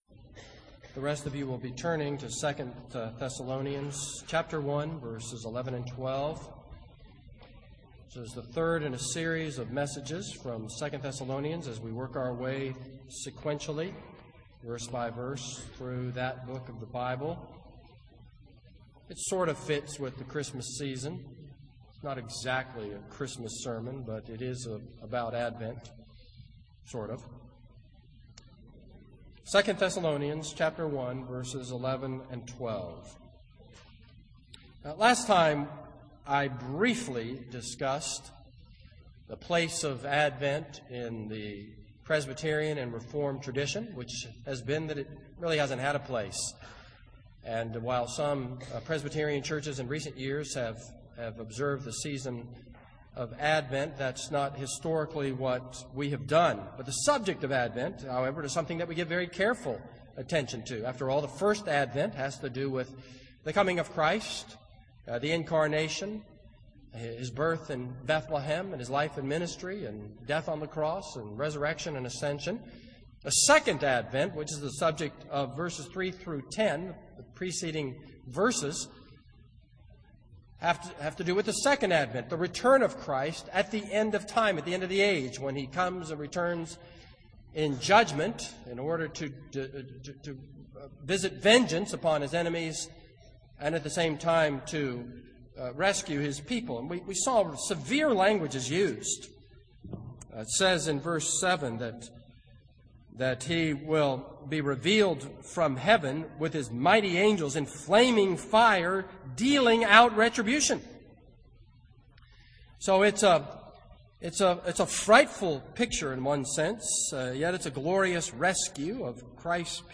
This is a sermon on 2 Thessalonians 1:11-12.